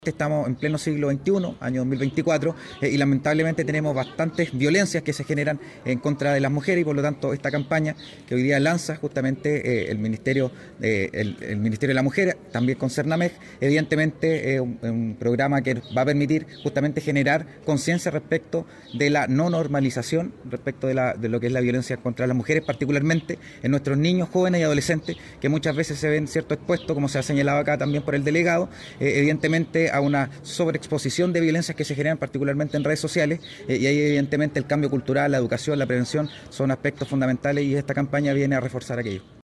Quien también destacó la implementación de esta campaña fue Boris Negrete, alcalde suplente de Concepción. Añadió que es una forma de responder a la violencia contra la mujer a la que los jóvenes pueden verse expuestos en redes sociales.